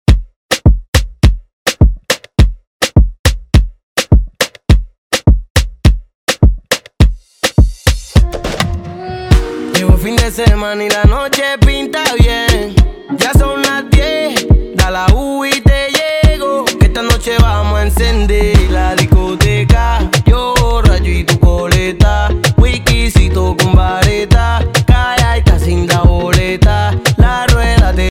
DJ